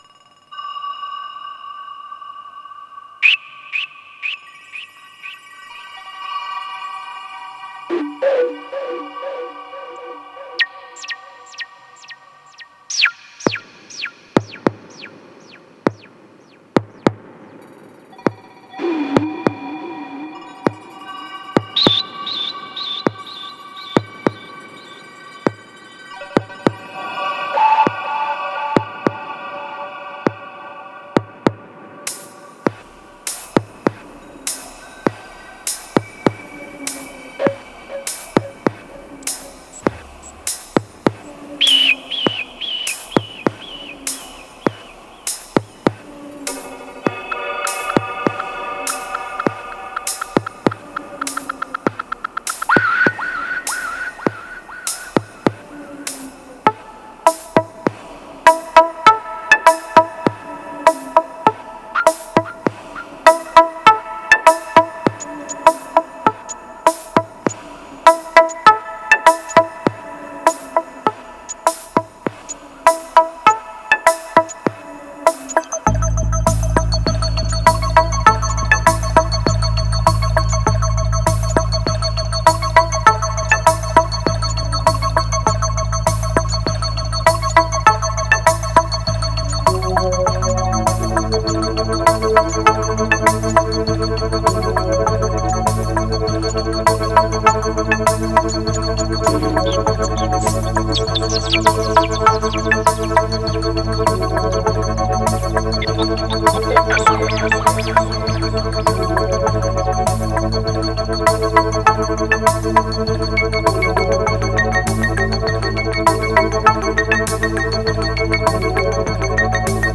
2531📈 - 90%🤔 - 100BPM🔊 - 2017-01-06📅 - 1184🌟